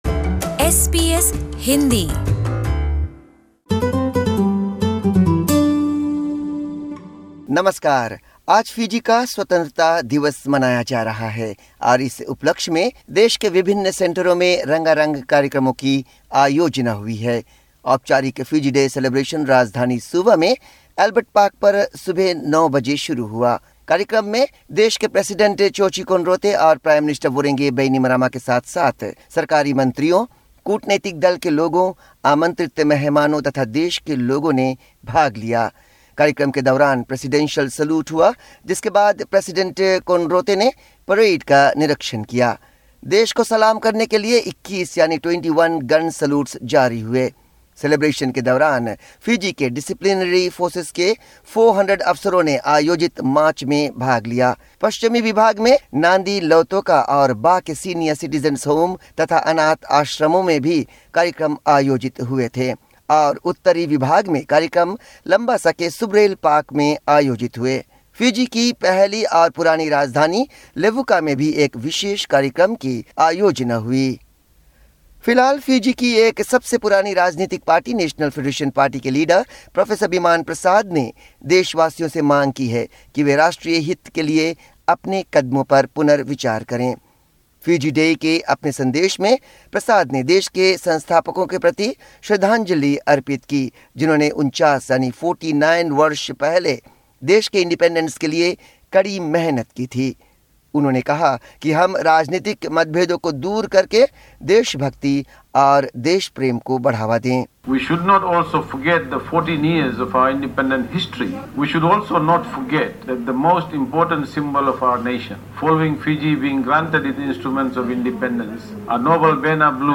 special report